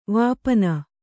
♪ vāpana